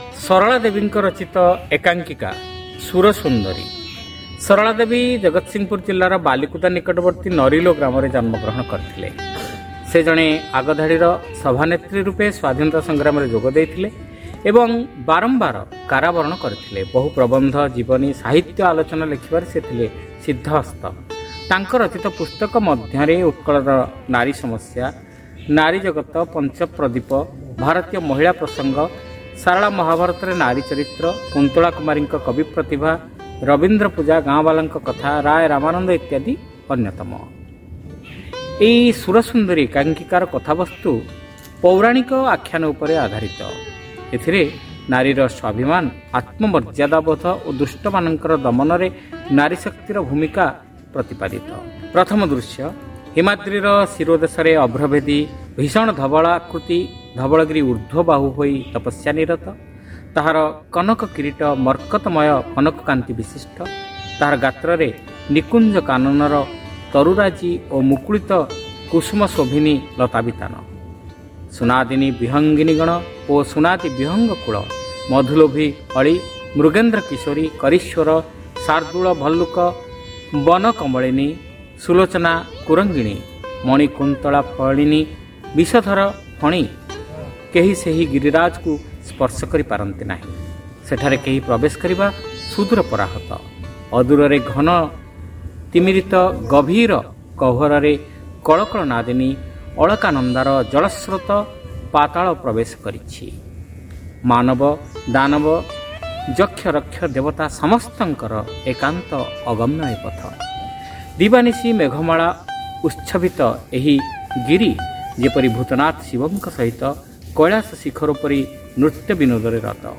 Audio One act Play : Sura Sundari (Part-1)